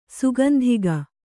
♪ sugandhiga